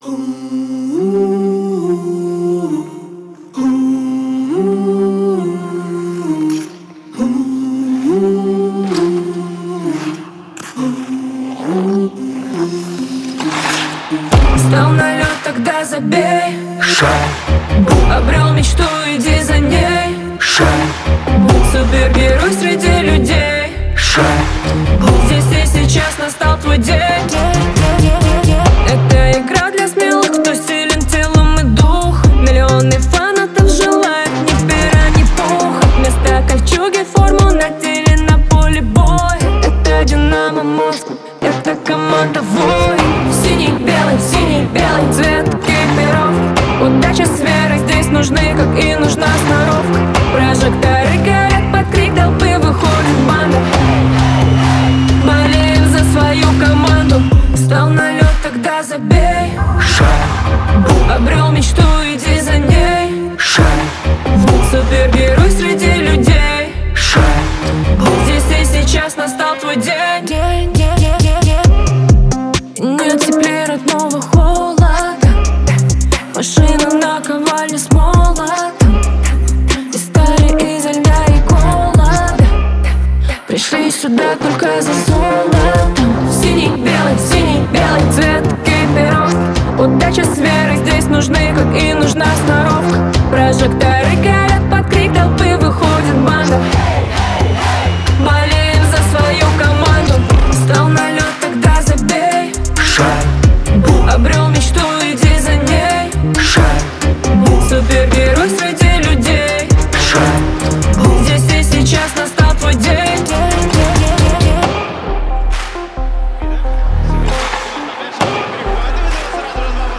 энергичная и задорная песня